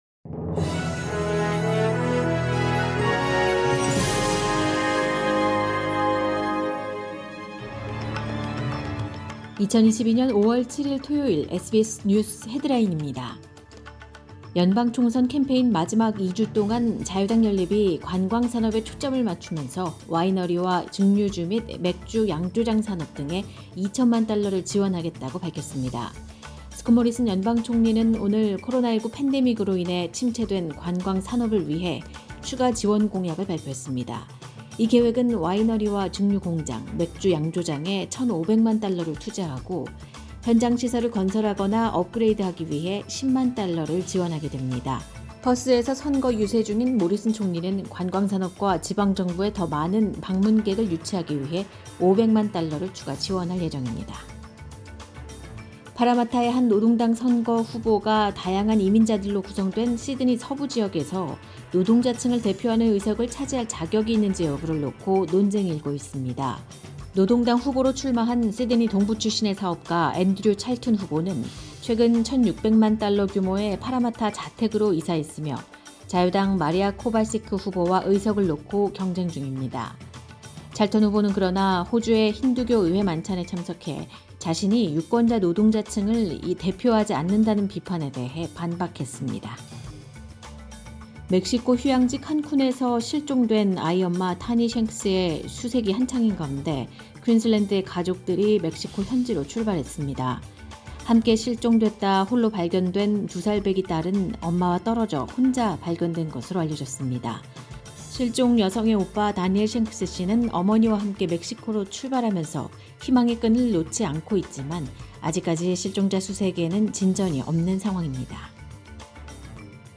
2022년 5월 7일 토요일 SBS 한국어 간추린 주요 뉴스입니다.